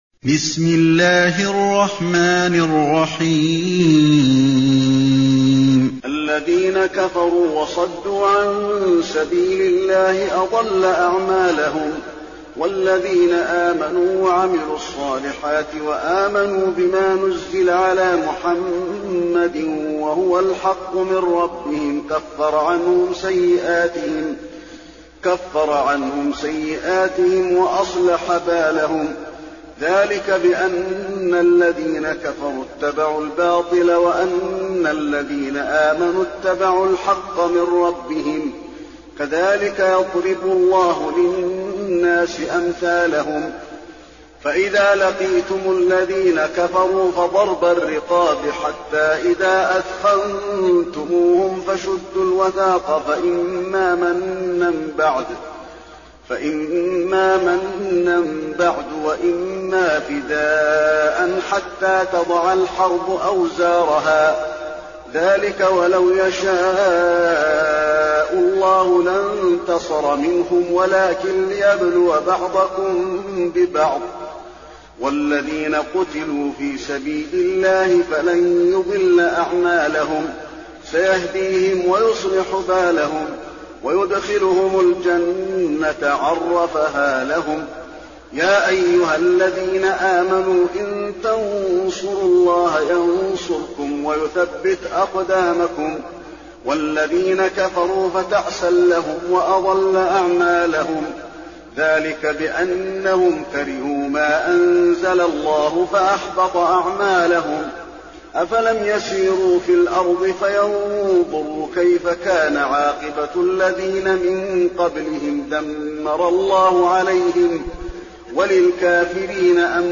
المكان: المسجد النبوي محمد